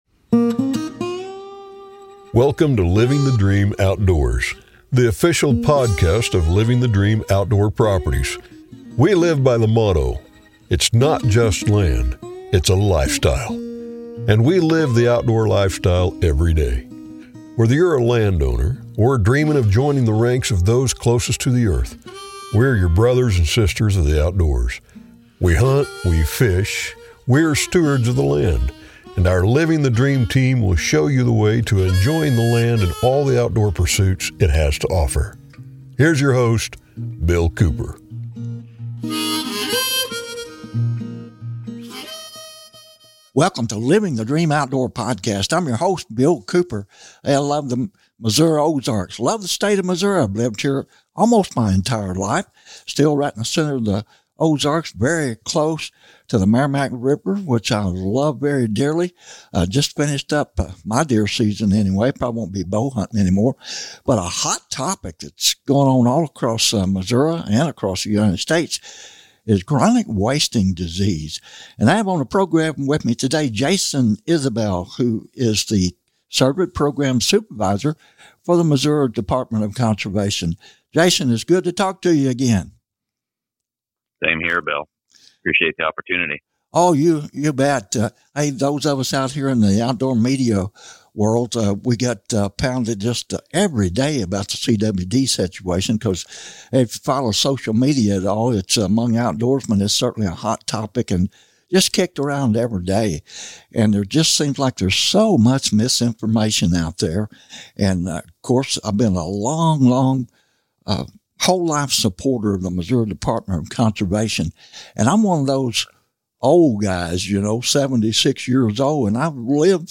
CWD Discussion